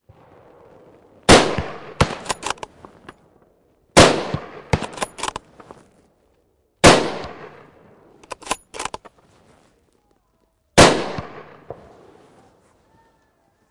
附近有枪声
描述：中距离的枪声计算机化的声音
标签： 循环 效果 射击 中距离
声道立体声